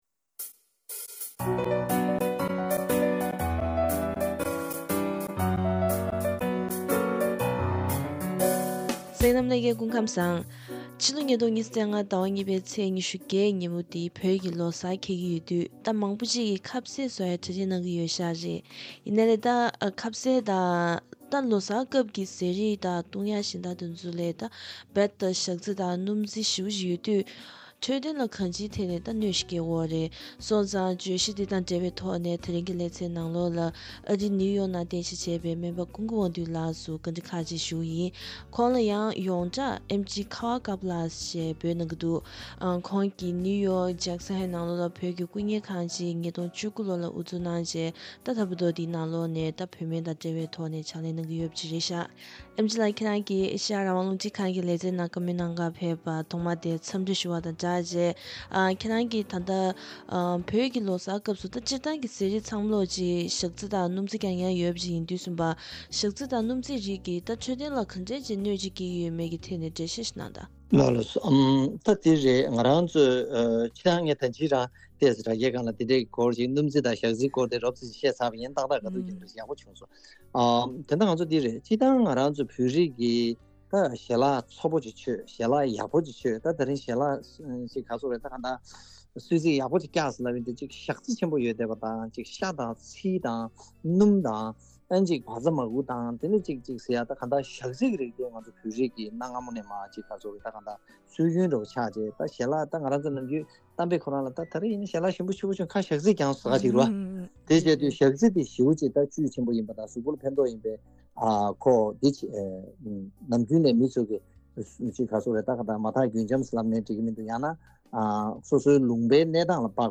བོད་ཀྱི་ལོ་གསར་སླེབས་ལ་ཉེ་བས་ཟས་དང་སྤྱོད་ལམ་ལ་ཉམས་འཇོག་དགོས་བའི་སྐོར་ལ་བཅར་འདྲི།